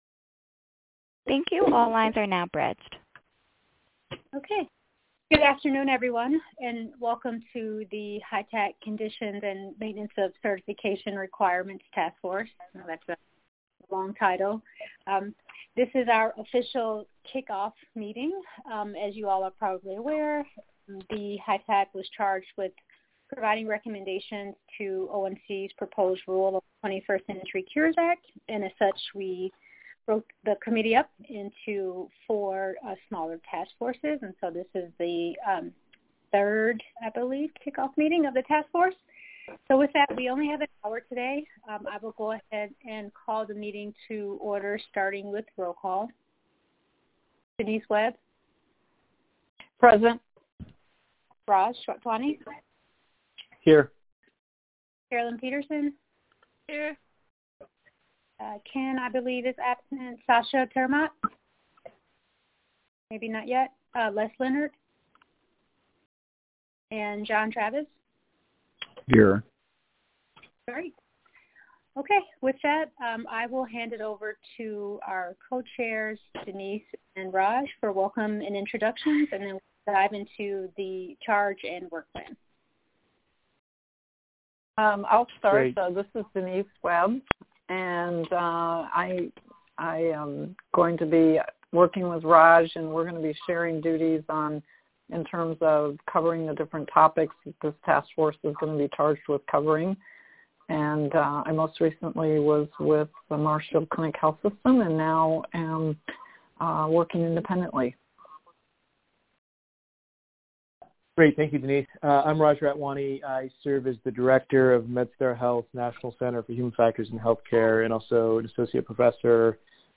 Conditions and Maintenance of Certification Requirements (CMC) Task Force Meeting Audio 3-5-2019